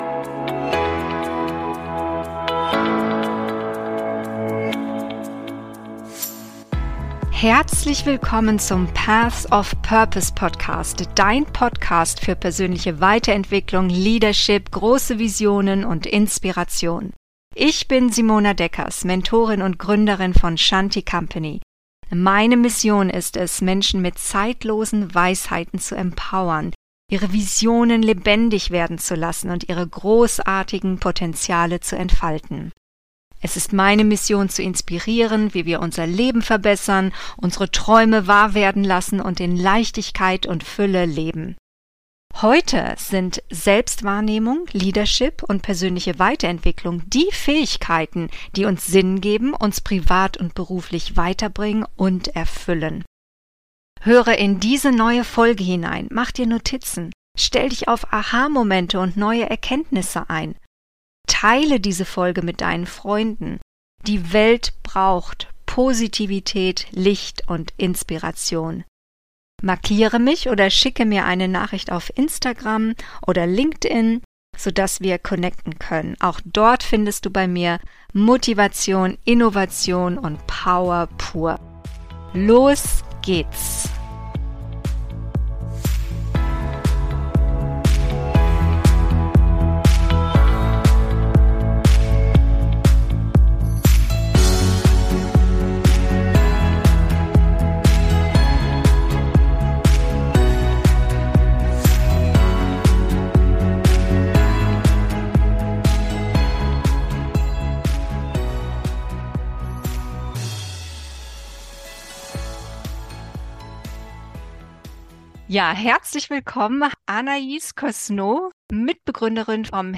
Stark mit Immobilien - Interview